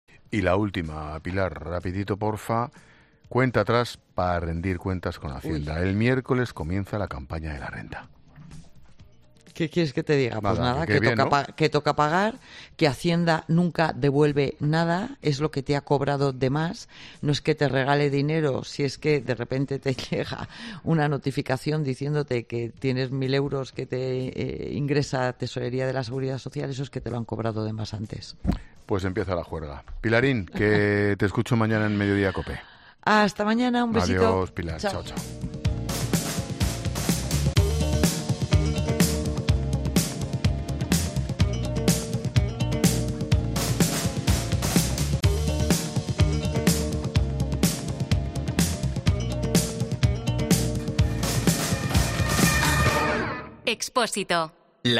Pilar García de la Granja, experta económica
Expósito aprende en Clases de Economía de La Linterna con la experta económica y directora de Mediodía COPE, Pilar García de la Granja, sobre el inicio de la campaña de la Renta